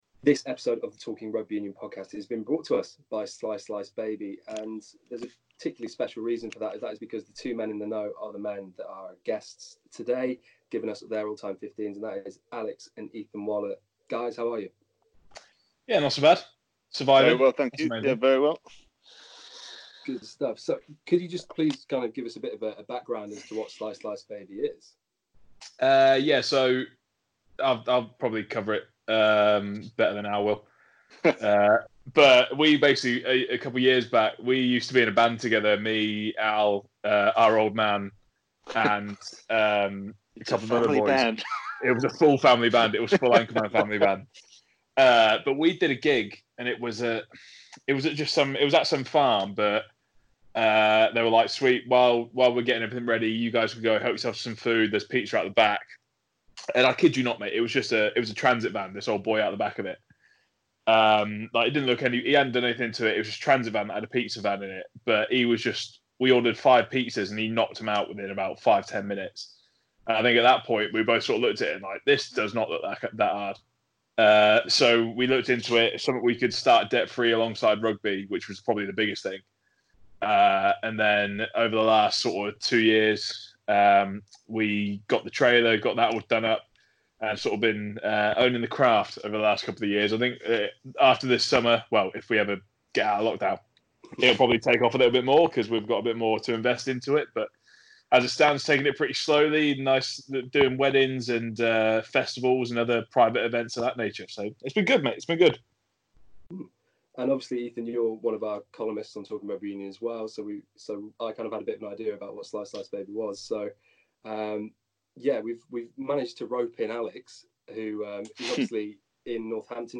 on a Skype call to talk things pizza and to pick out their all-time XVs.